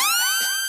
Siren TM88.wav